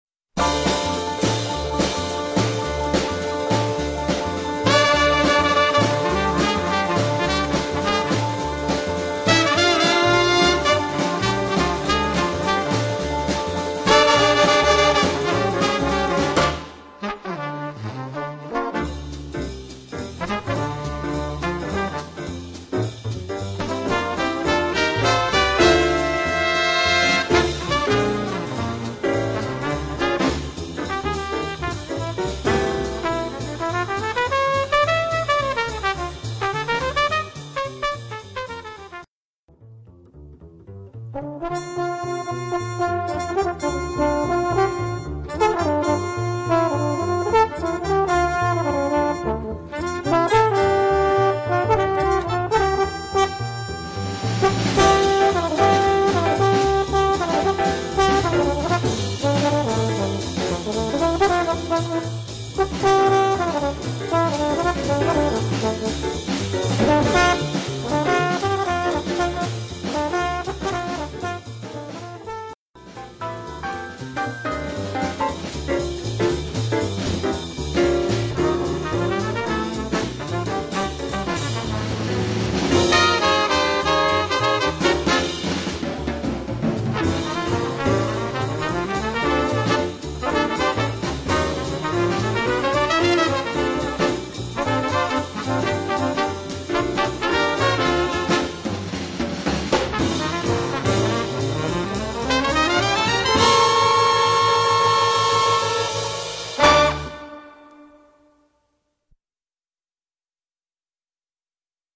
Voicing: Combo Sextet